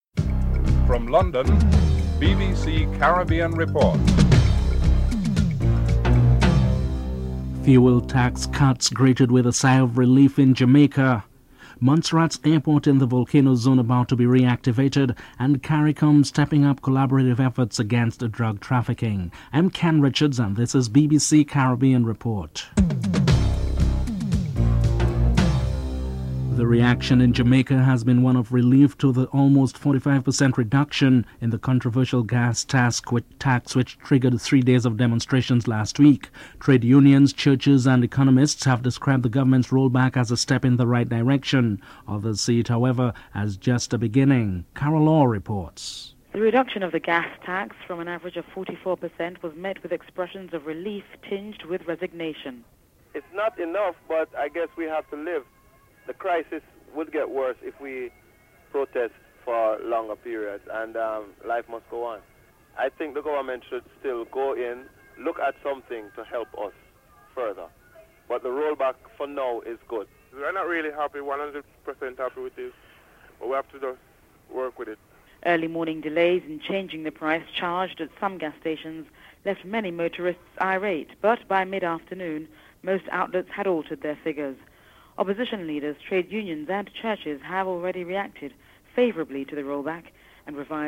The British Broadcasting Corporation
1. Headlines with anchor